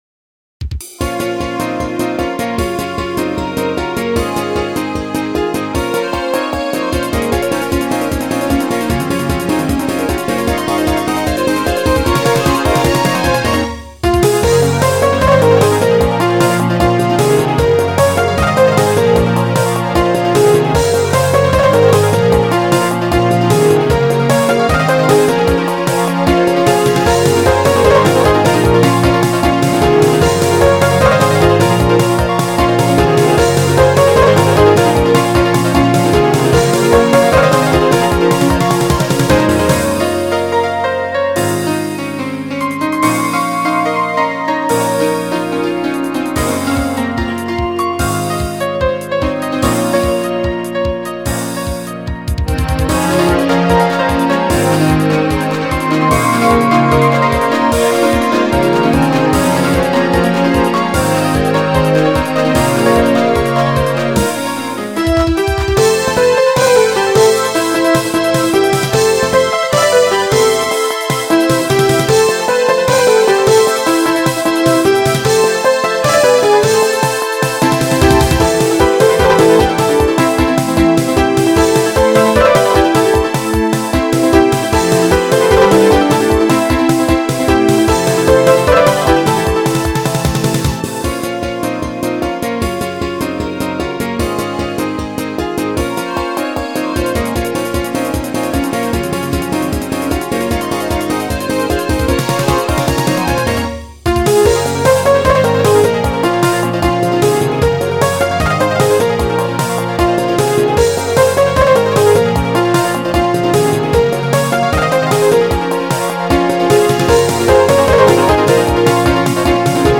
由Roland Sound Canvas 88Pro实机录制